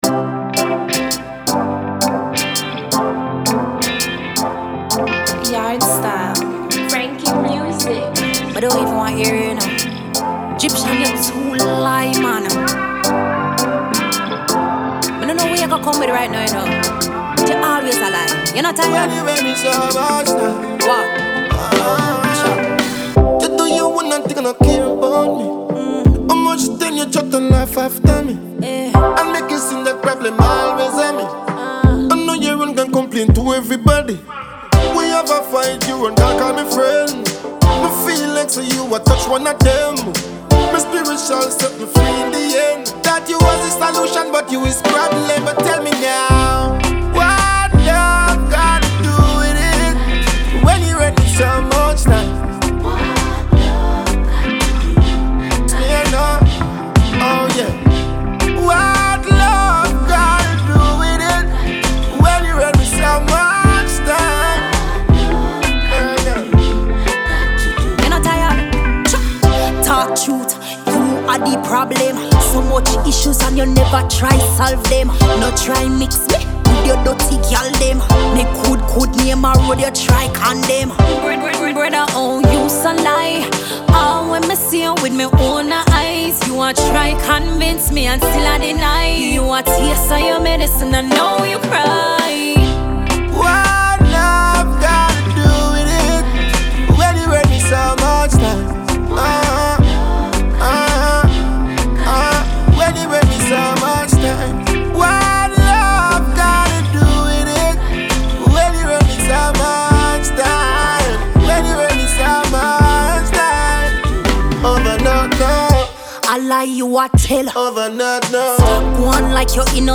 ce duo a été annoncé comme “chaud”…